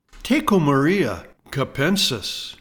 Pronounciation:
Te-CO-mar-EE-a ka-PEN-sis